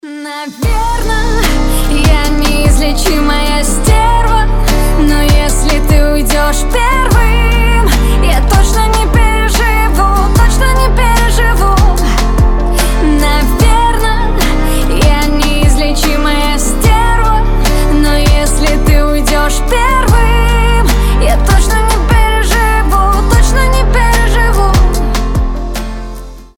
• Качество: 320, Stereo
гитара
женский голос